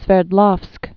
(sfĕrd-lôfsk)